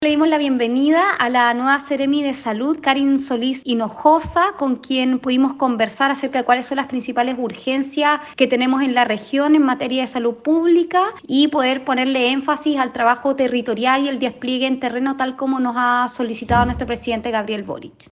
La máxima representante del ejecutivo en la Región, explicó que le solicitó a la Seremi de Salud, poner énfasis en el despliegue territorial y las principales urgencias que tiene la ciudadanía: